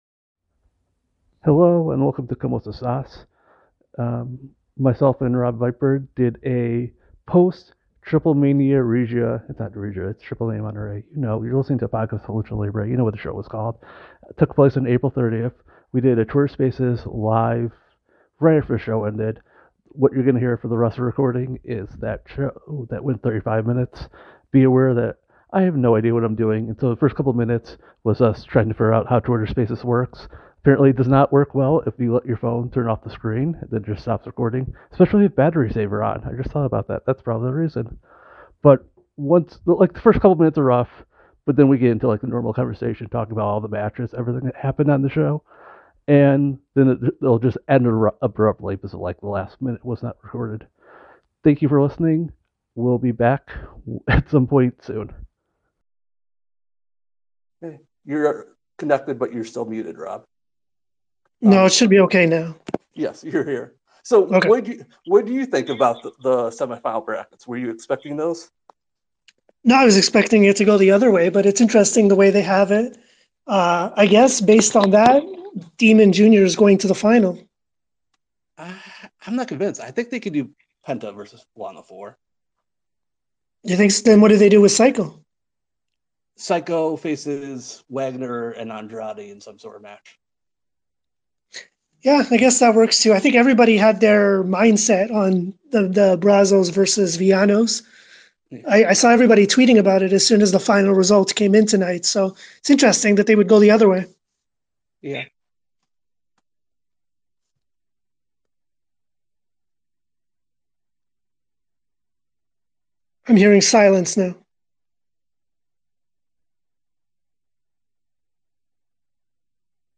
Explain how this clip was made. You can use OBS to record it afterward, add a muffled introduction, spend 15 minutes remembering how you upload a podcast and then do that, so that’s what I’ve done.